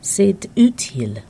Click each phrase to hear the pronunciation.